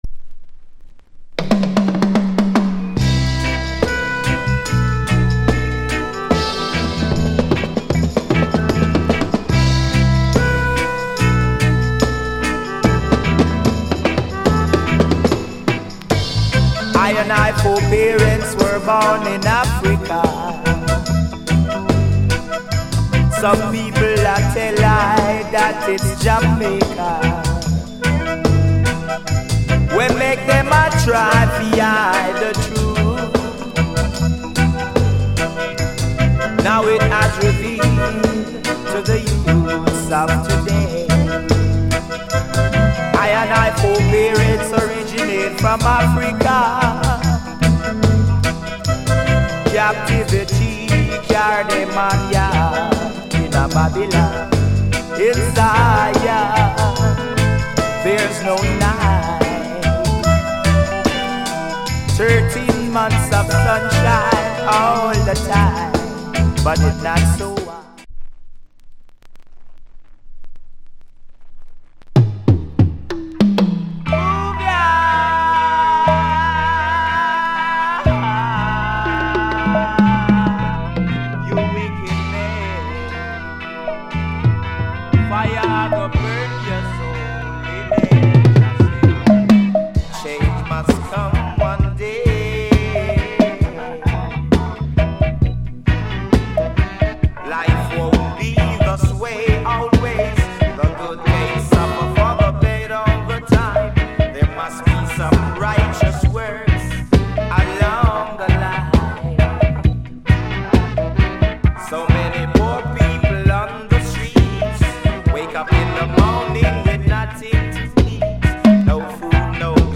Genre Roots Rock / Male Vocal